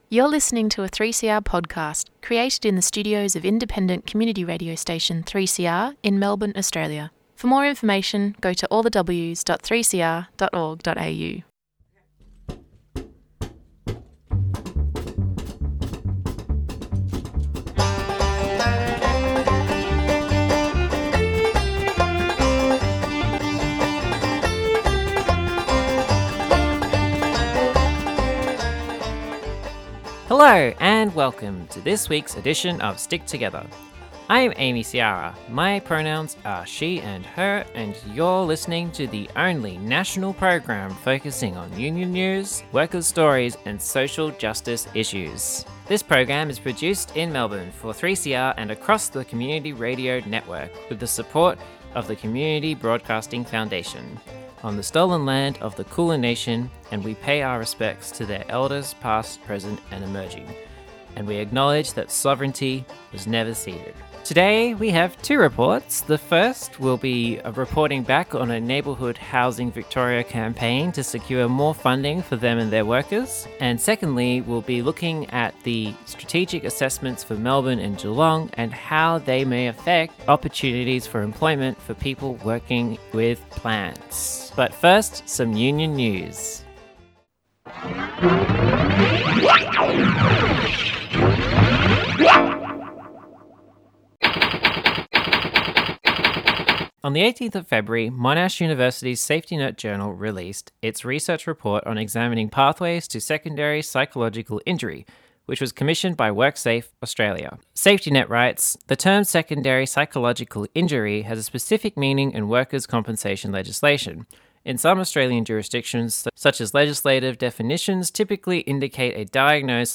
Stick Together is Australia’s only national radio show dedicated to union and workplace justice issues. In addition to a weekly wrap of union news, the team bring you indepth stories and interviews from the frontlines of the workers' movement.Stick Together is recorded at 3CR, and broadcast on community radio stations around the country via the Community Radio Network.